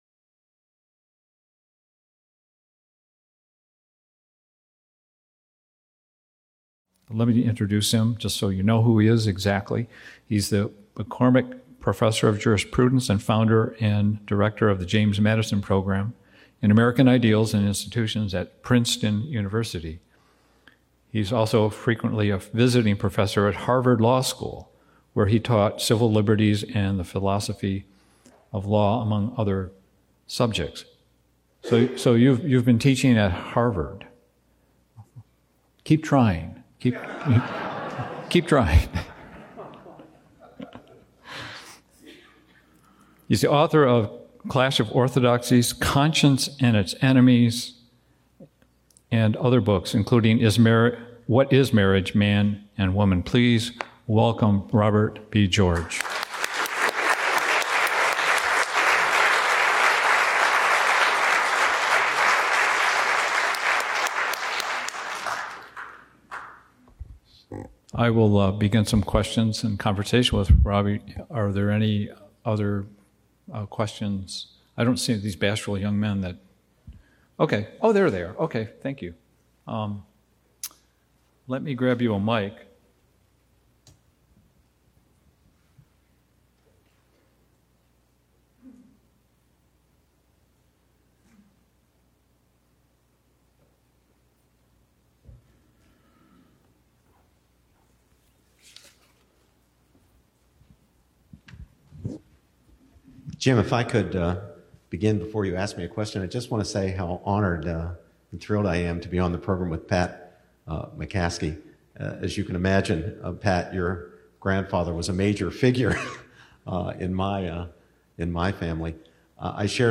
Duration: 43:31 — Talk delivered on Friday, October 12, 2018